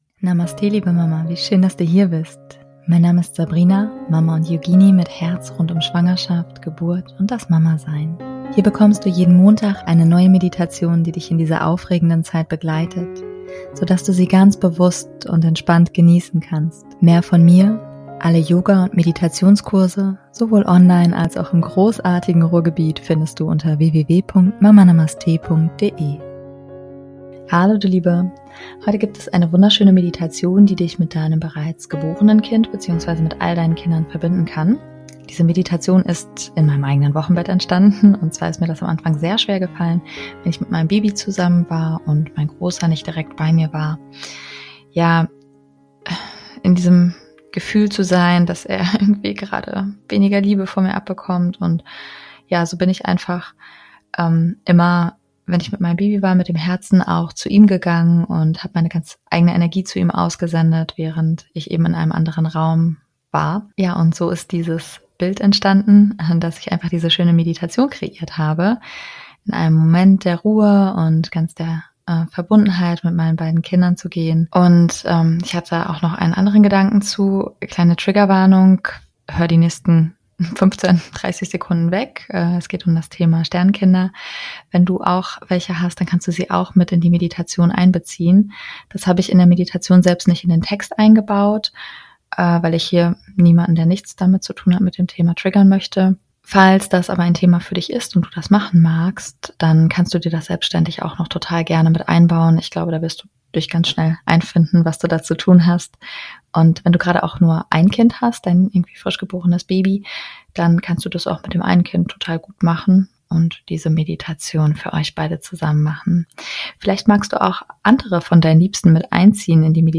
#173 - Verbundenheit mit allen Geschwisterkindern - Meditation